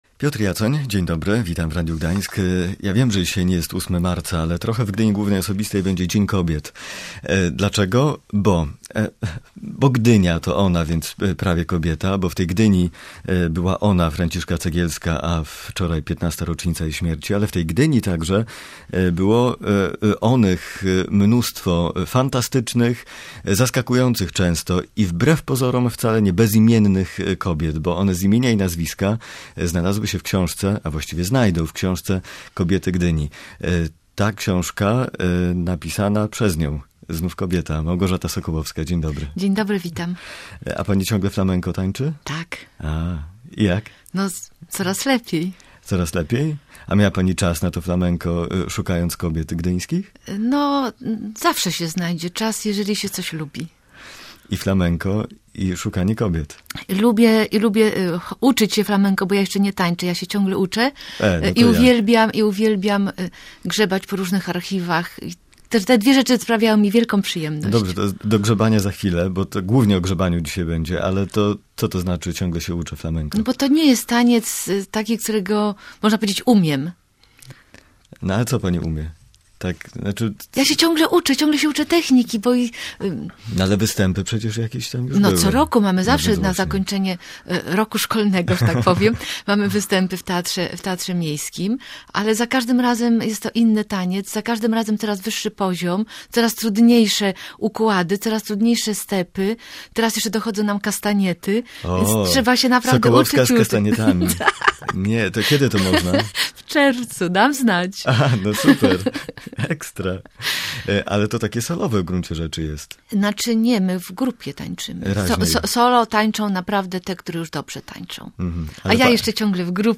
Prowadzący audycję Piotr Jacoń wspominał Franciszkę Cegielską, ponieważ w czwartek minęła 15. rocznica jej śmierci.